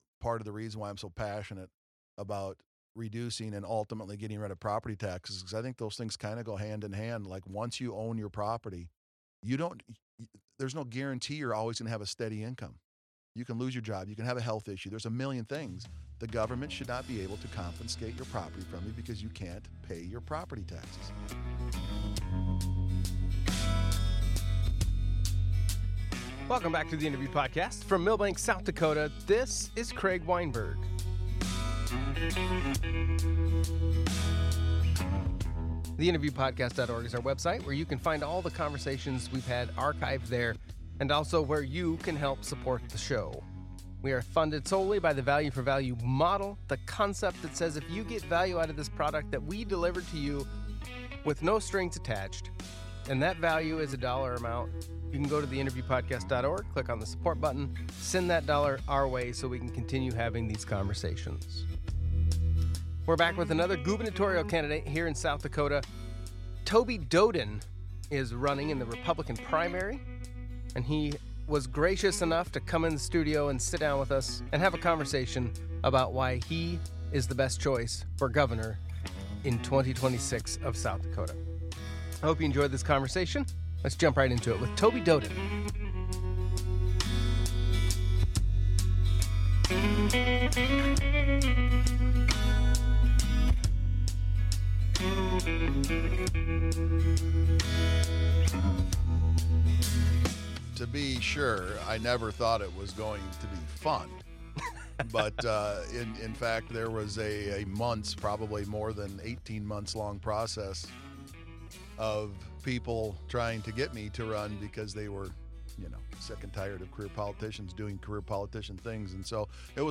interviews people from around the world on a variety of topics.